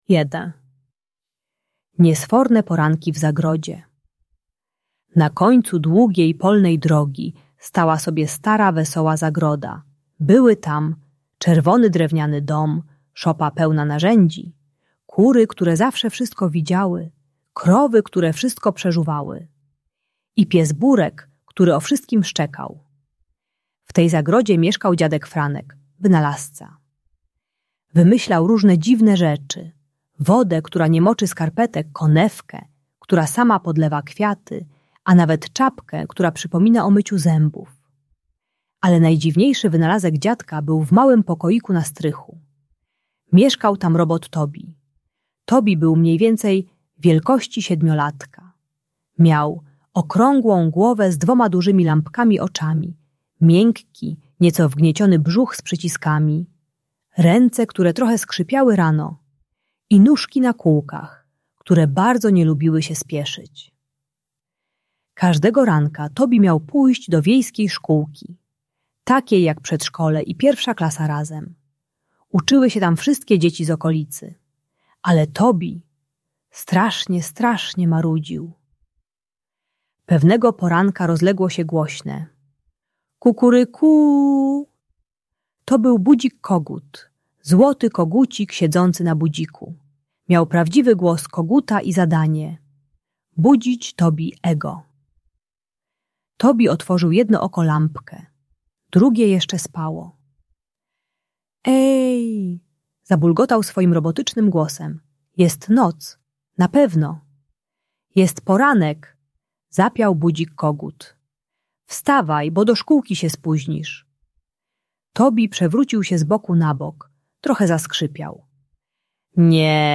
Niesforne poranki w zagrodzie - Szkoła | Audiobajka